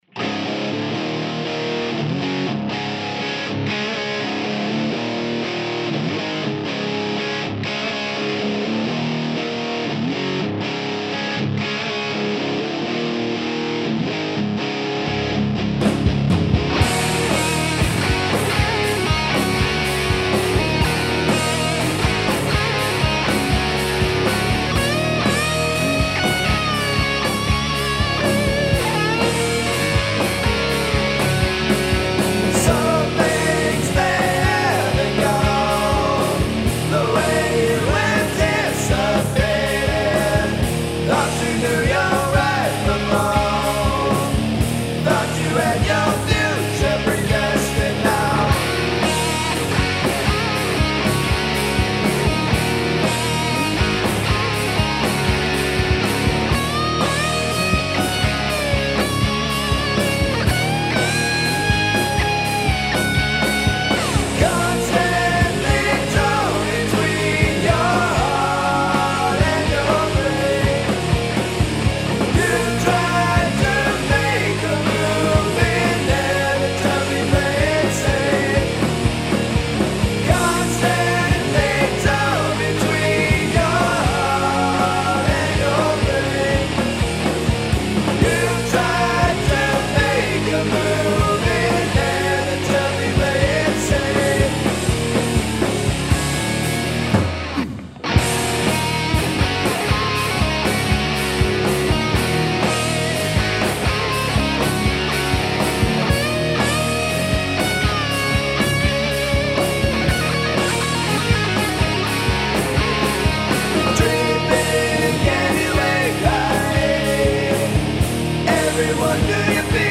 Rock & Roll
Rock/Hard-rock